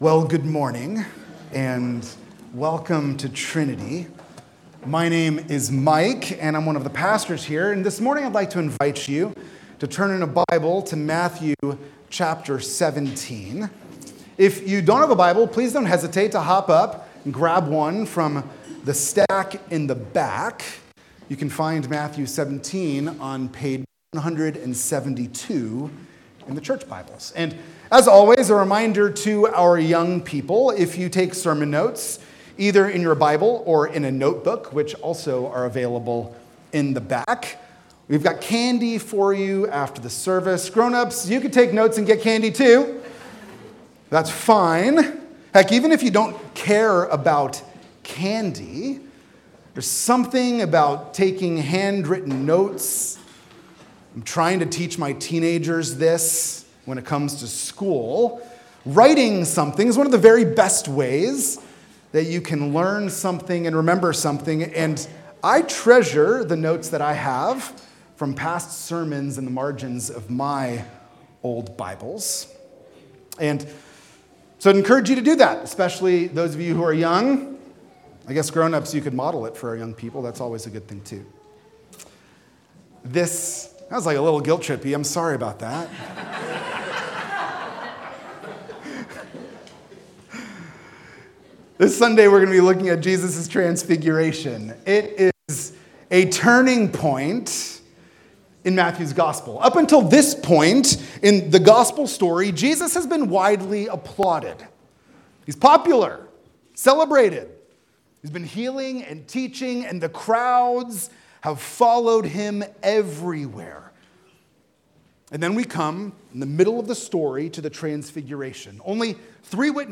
This sermon explains that the Transfiguration in Matthew 17 reveals Jesus’ true divine glory and marks a turning point from his popularity toward the suffering and death that will follow, confirming that he is the beloved Son of God to whom we must listen.